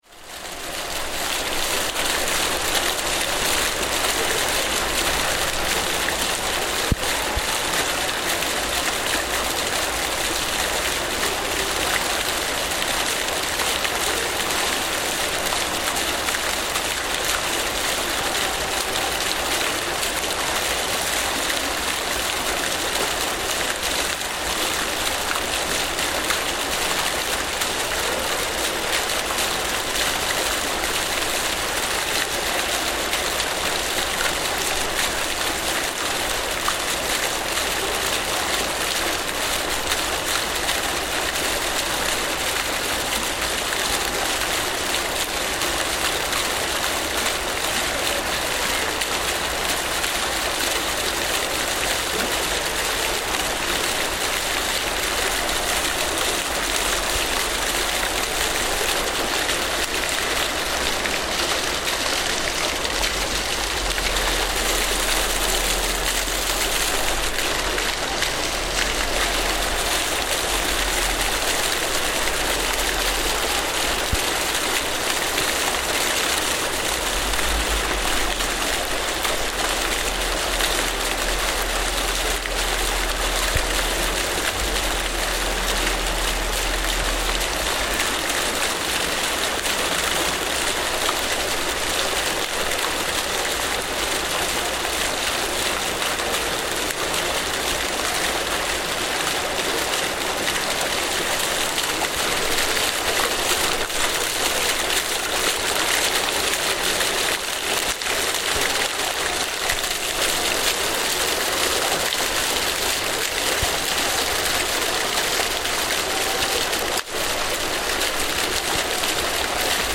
The Folies were designed by the architect Bernard Tschumi for the Parc de la Villette in Paris.
This is a recording from Folie L6, which features a spiral staircase waterfall - here we can hear the water tumbling down into a pool at ground level, while the sounds of the park continue around us.